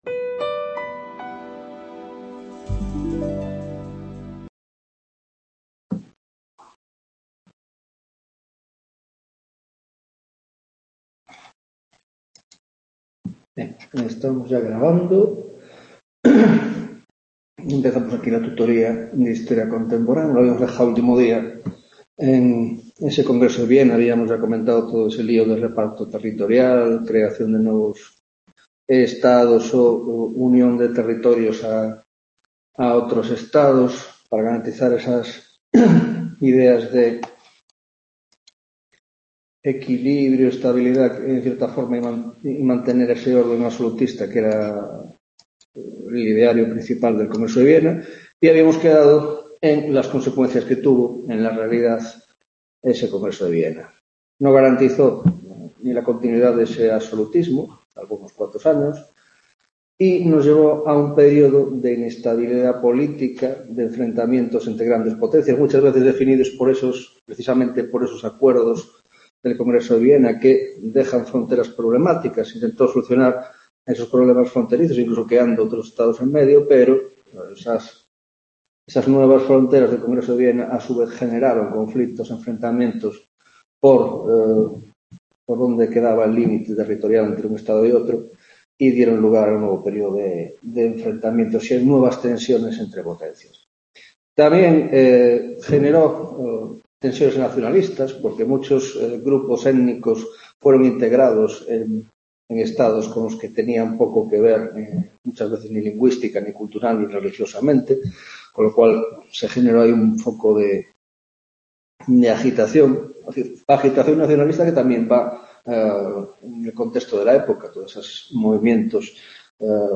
9ª Tutoría de Historia Contemporánea - Restauración Absolutista y Congreso de Viena (2ª parte) y Revoluciones Liberales